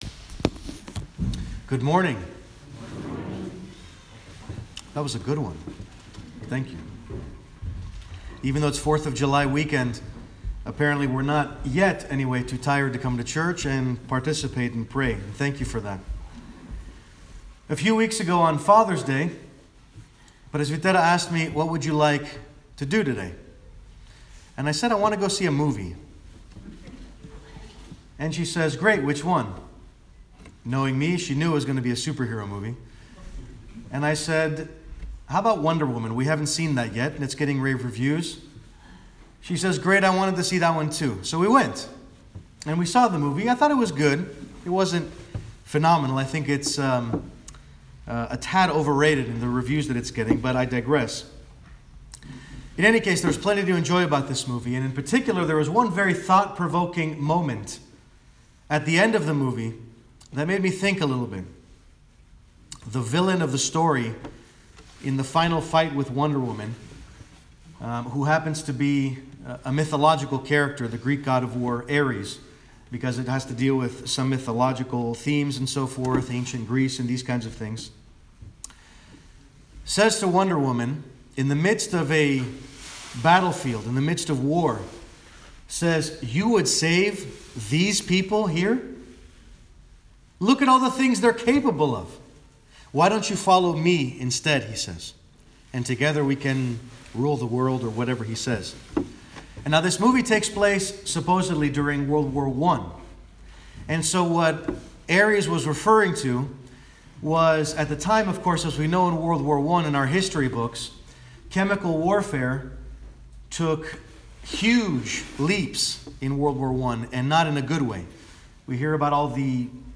Sermon Audio- “The Errors of the People”